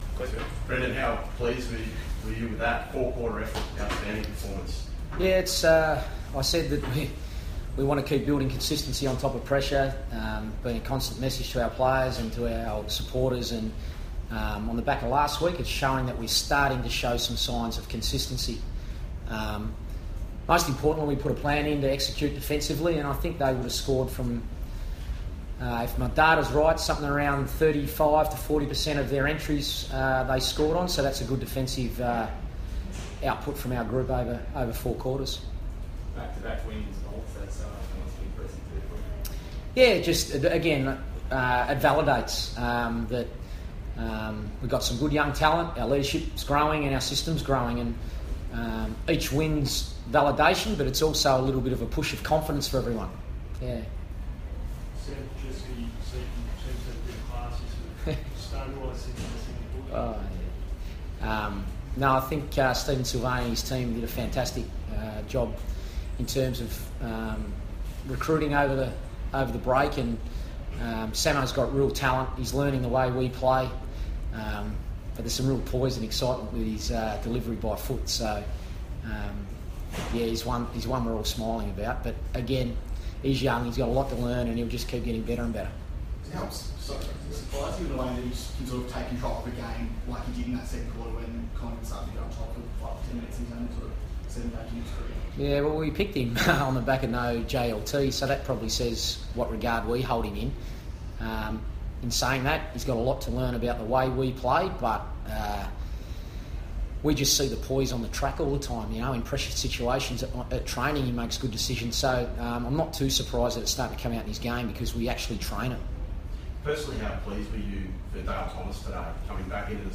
Carlton coach Brendon Bolton speaks to the media after the Blues' 23-point win over arch-rival Collingwood.